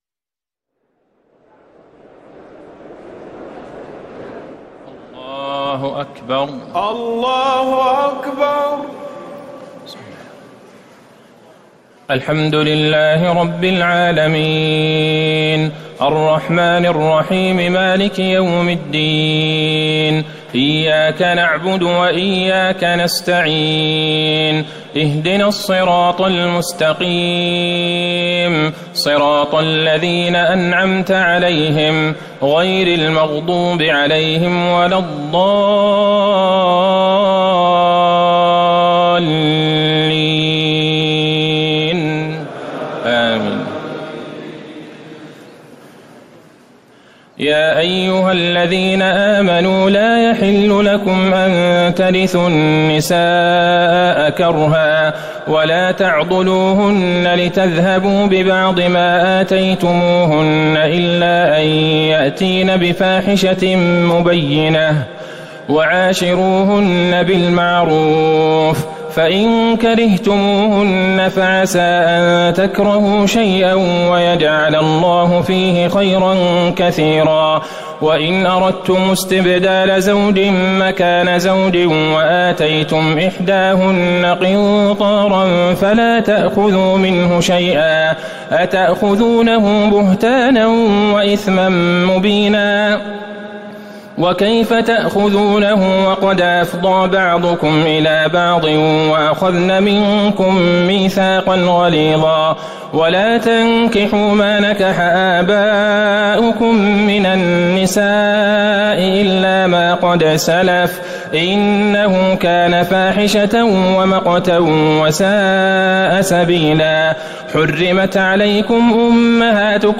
تراويح الليلة الرابعة رمضان 1439هـ من سورة النساء (19-87) Taraweeh 4 st night Ramadan 1439H from Surah An-Nisaa > تراويح الحرم النبوي عام 1439 🕌 > التراويح - تلاوات الحرمين